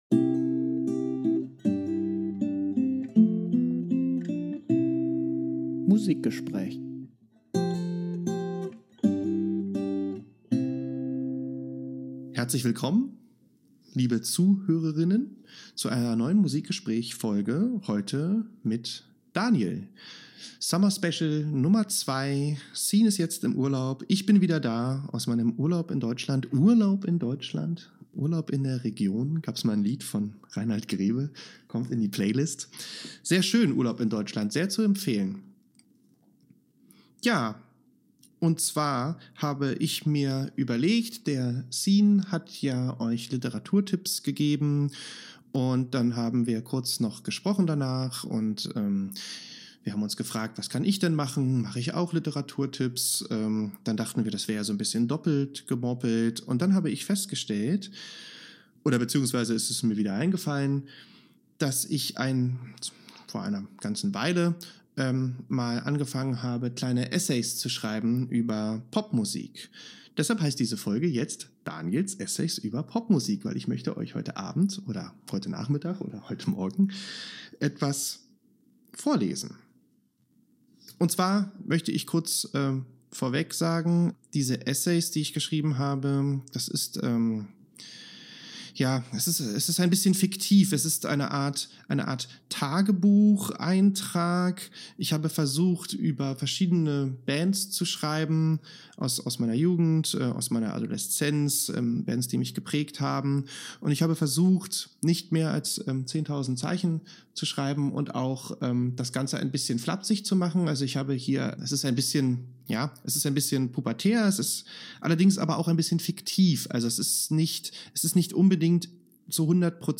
Summerspecial Solo-Folge